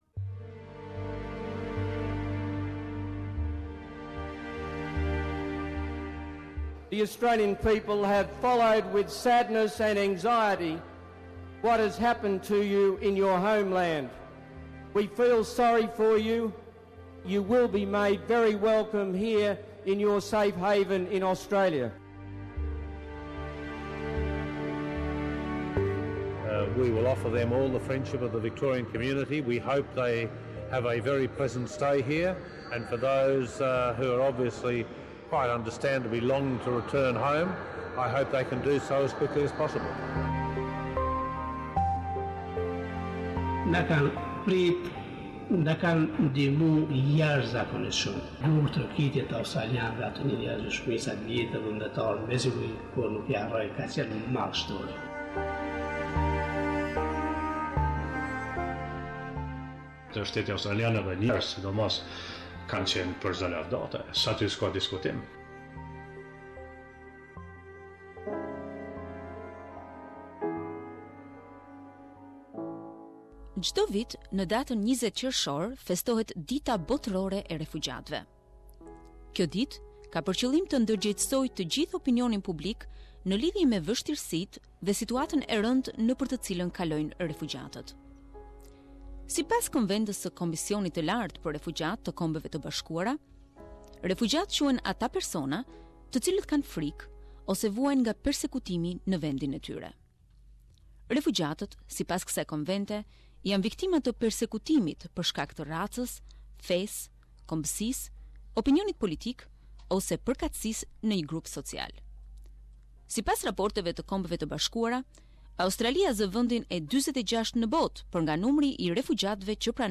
Dreka e Fundit - Documentary - Part 1